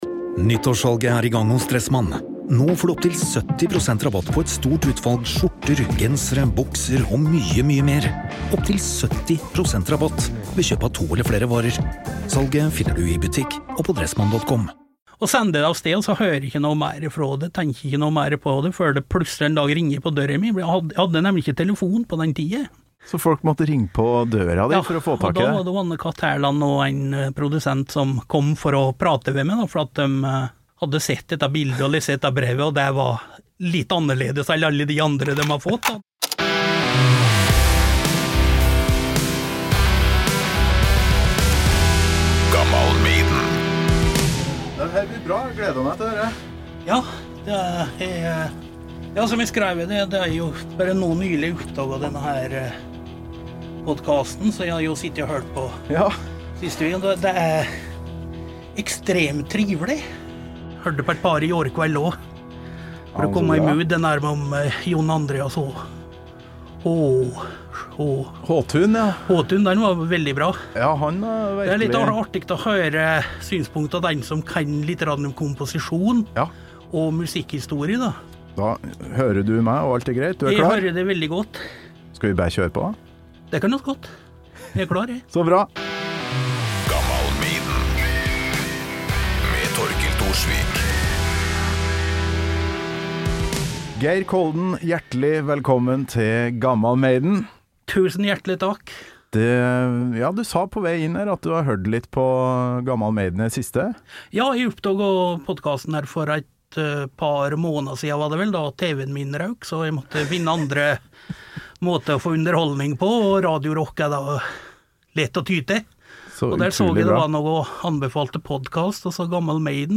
og som jeg har savna den herlige dialekta hans. Hvordan var det å være heavy-fyr i Skjåk?